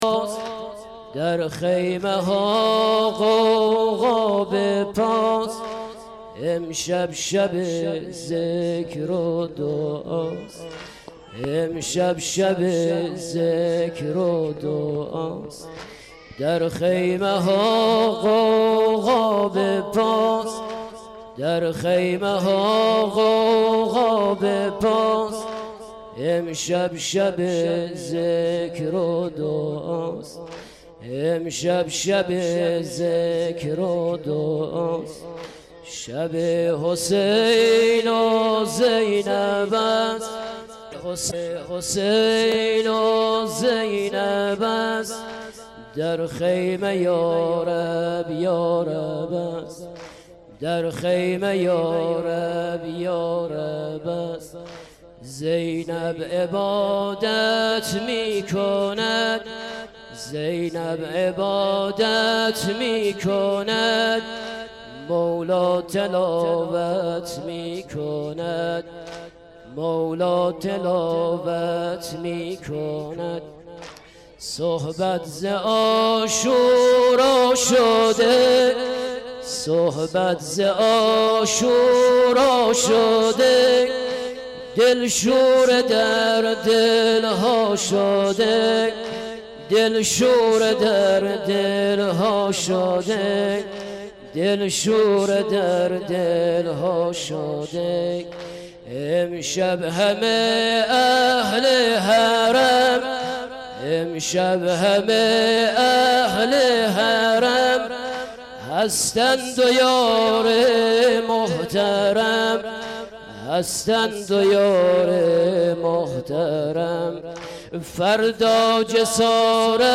سینه زنی سنگین
• شب10 محرم93 هیئت ثارالله علیه السلام - سینه زنی سنگین.mp3
شب10-محرم93-هیئت-ثارالله-علیه-السلام-سینه-زنی-سنگین.mp3